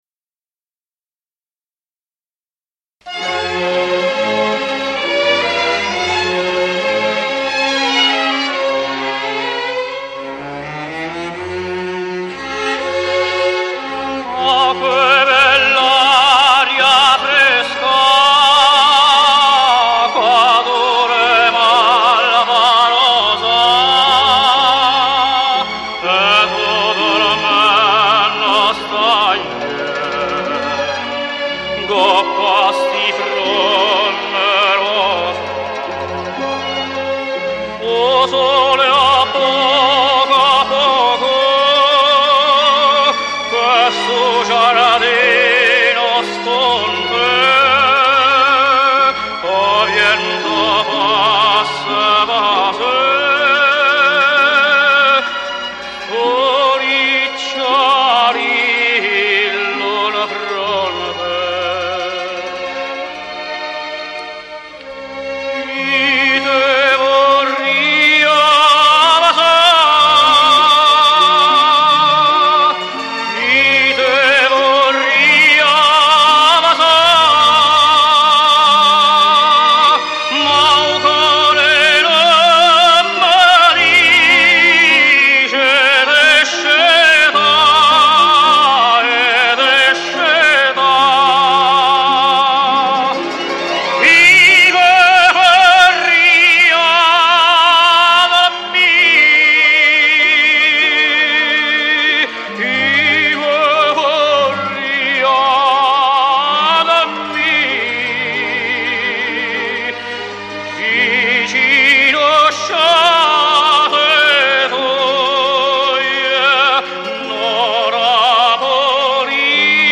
ORCHESTRA
a tenor of extraordinary versatility and tonal quality.
a programme of arias and Neopolitan songs